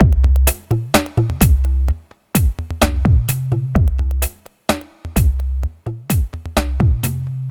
Downtempo 11.wav